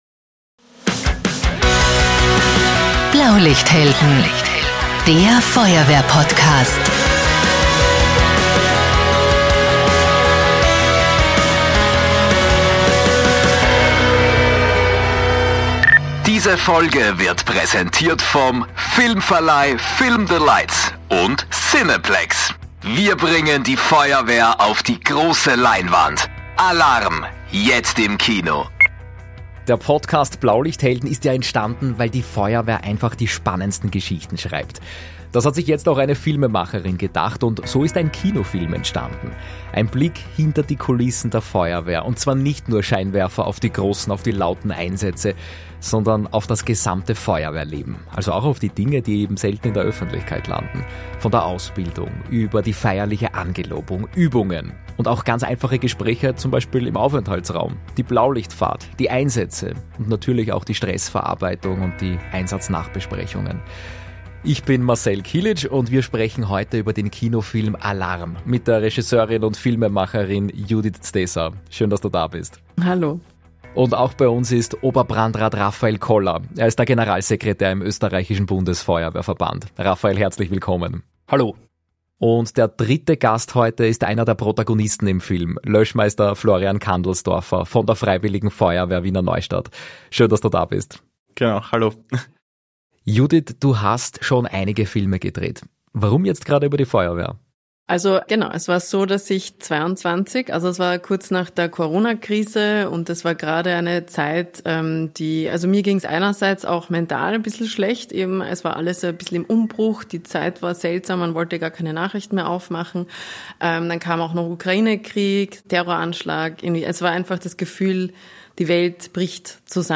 In dieser Folge melden wir uns „live“ von der großen Award-Show im Ö3-Haus am Wiener Küniglberg.